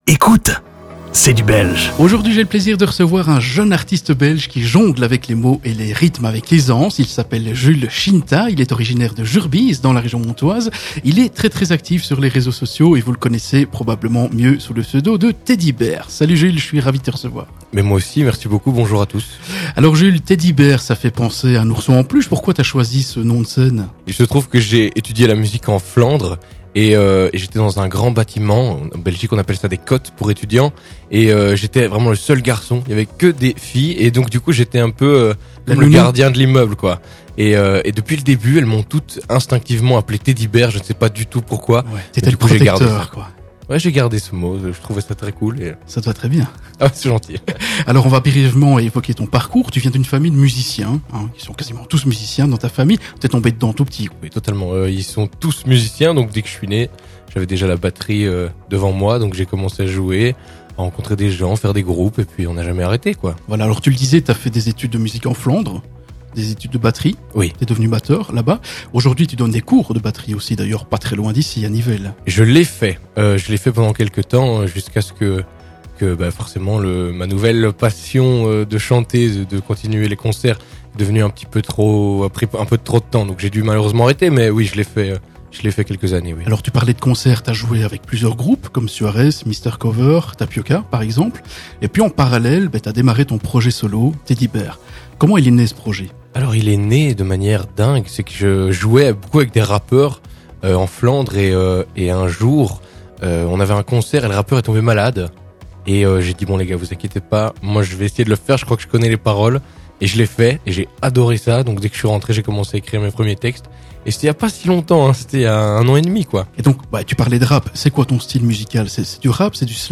Retrouvez l'intégralité de cette sympathique interview dans ce podcast